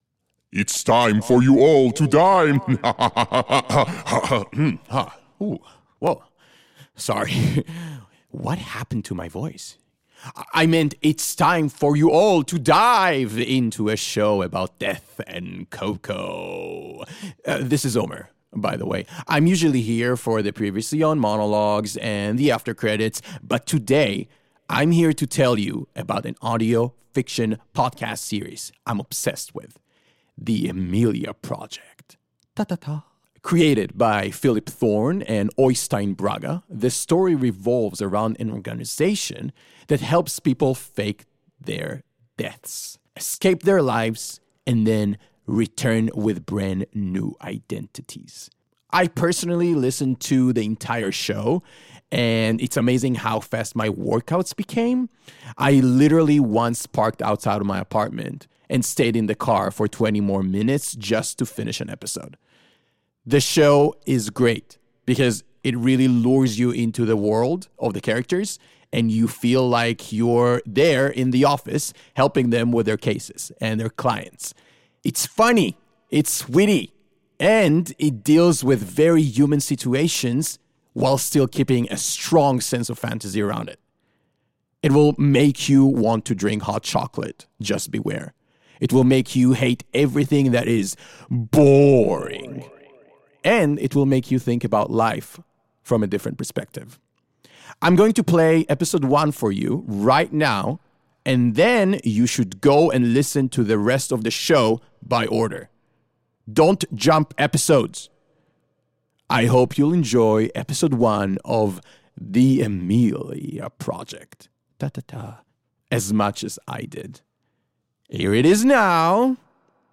An Audio Fiction Podcast Series